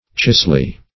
Search Result for " chisley" : The Collaborative International Dictionary of English v.0.48: Chisley \Chis"ley\ (ch[i^]z"l[y^]), a. [AS. ceosel gravel or sand.